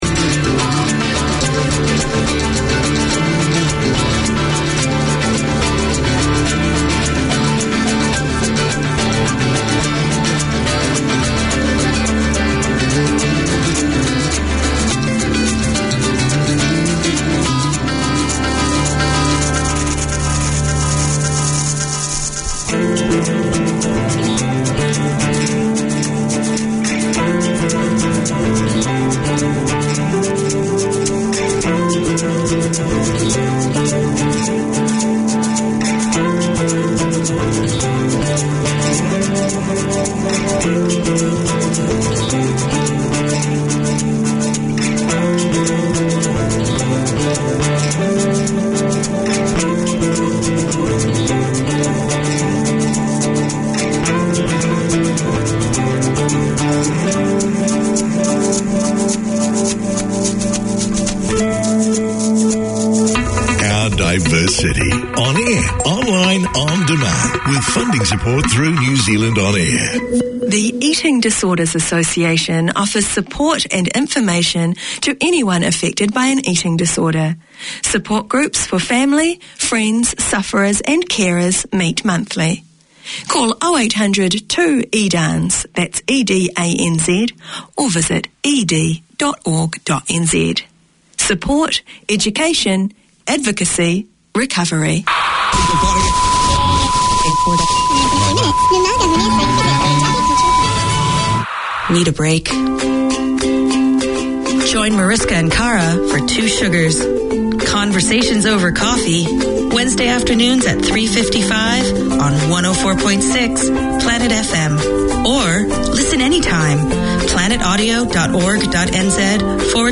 Community Access Radio in your language - available for download five minutes after broadcast.
Garden Planet tackles everything from seasonal gardening and garden maintenance, to problem-solving, troubleshooting, and what to plant and when. Tune in for garden goss, community notices and interviews with experts and enthusiasts on all things green or growing.